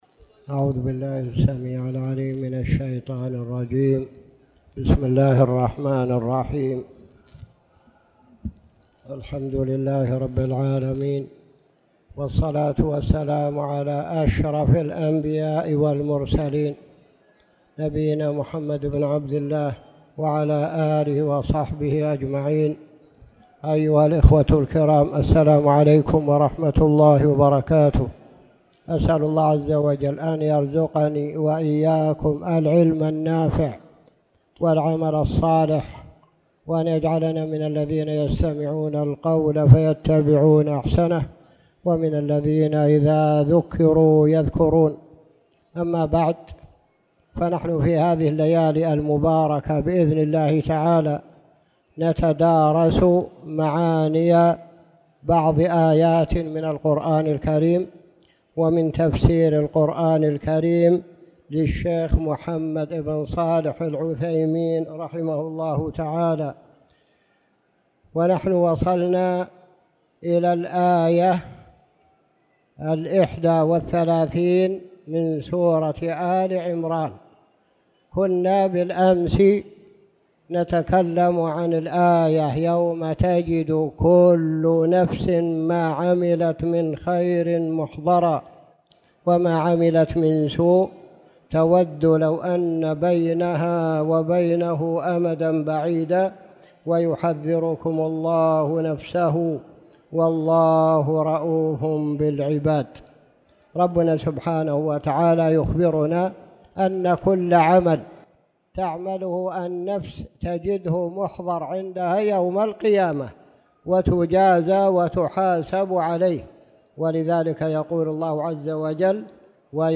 تاريخ النشر ٤ جمادى الأولى ١٤٣٨ هـ المكان: المسجد الحرام الشيخ